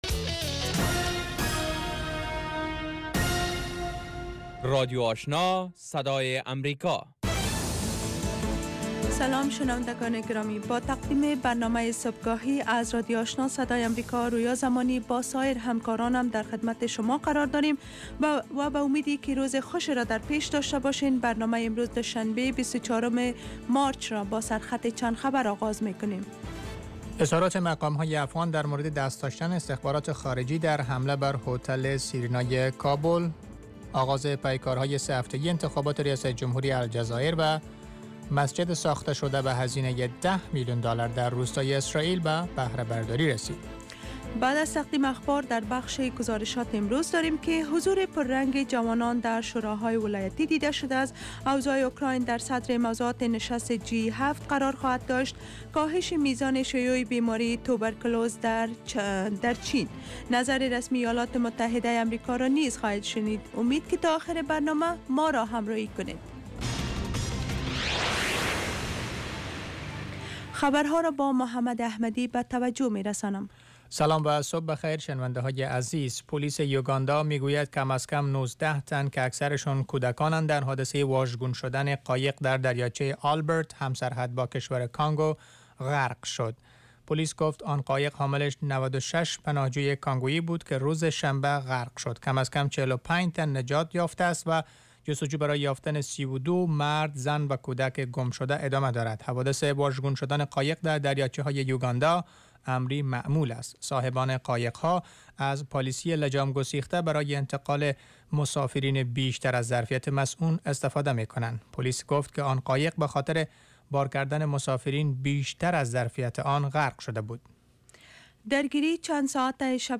برنامه خبری صبح